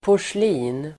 Uttal: [por_sl'i:n]